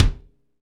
Index of /90_sSampleCDs/Northstar - Drumscapes Roland/KIK_Kicks/KIK_Funk Kicks x
KIK FNK K0KL.wav